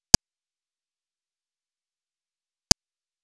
Tyrannus savana - Tijereta
tijereta.wav